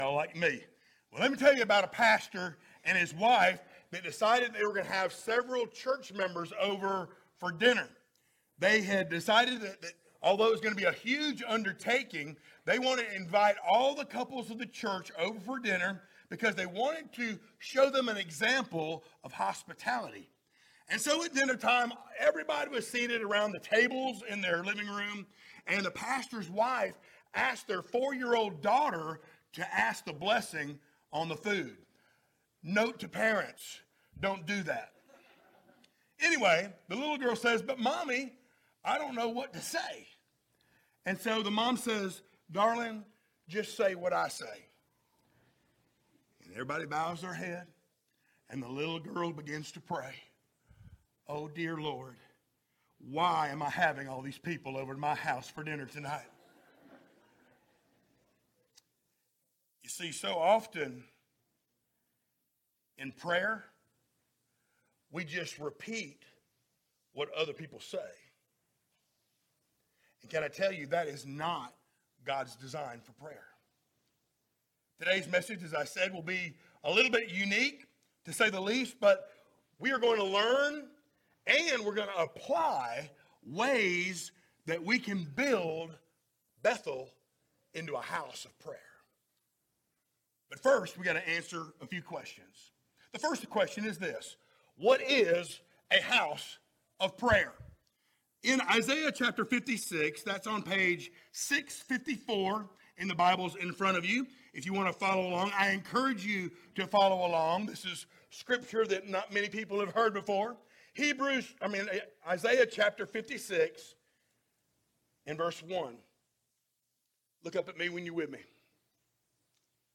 sermons Passage: Isaiah 56:6-8 Service Type: Sunday Morning Download Files Notes Topics